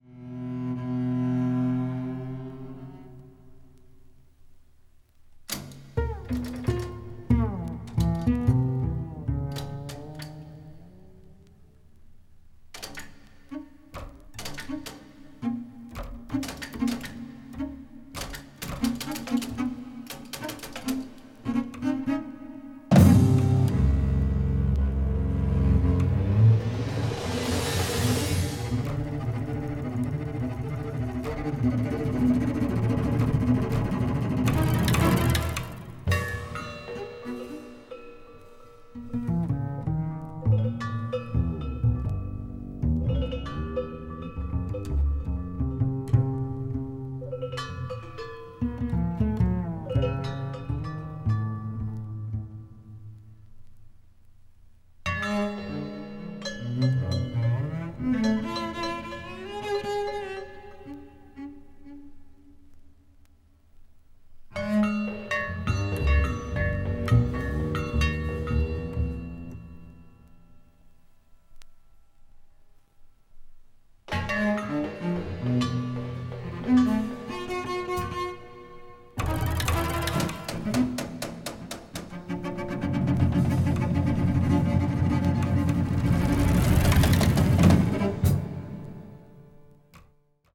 subtitled "Music for Bass, Percussion, and Tape